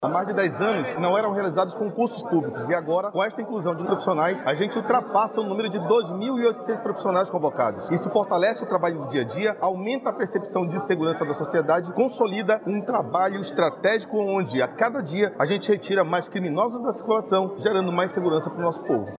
O secretário de Estado de Segurança Pública, Vinícius Almeida, explica que os novos profissionais vão fortalecer a atuação da PC/AM em seus vários núcleos.